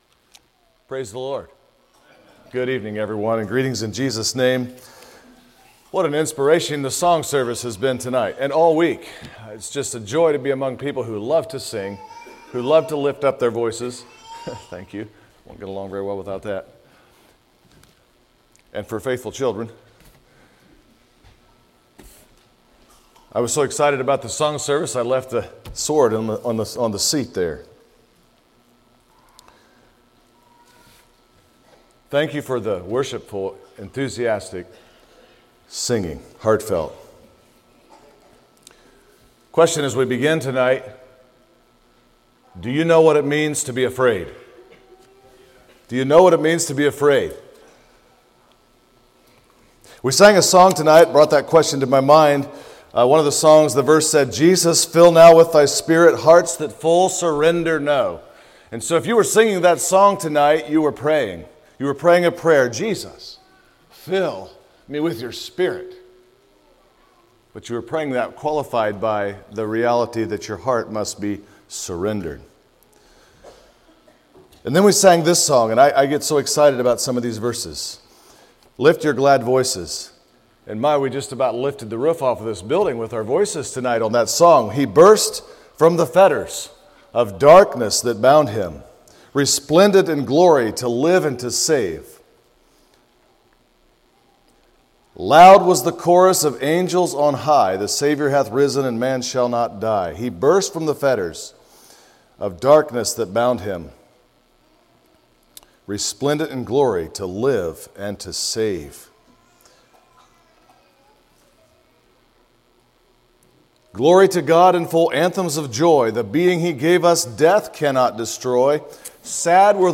A message from the series "Bible Boot Camp 2024."